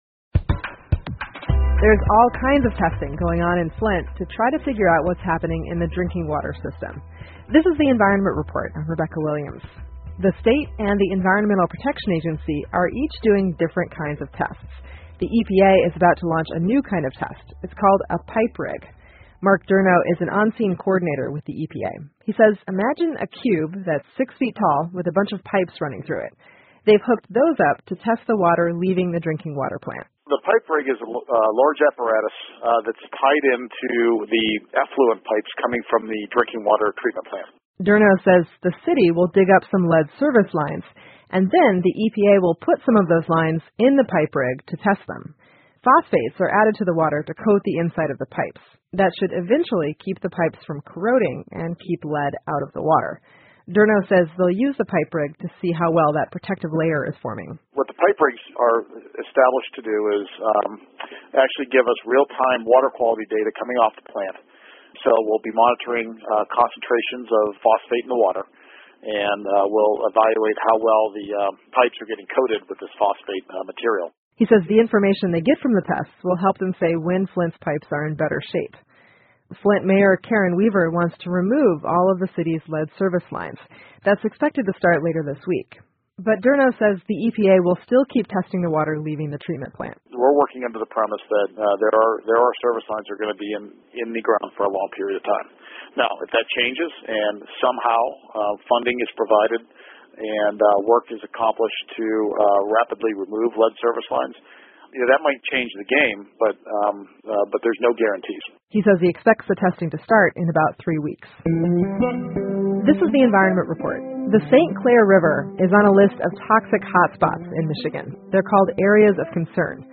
密歇根新闻广播 密环保局就弗林特饮用水水管开始了新一轮测验 听力文件下载—在线英语听力室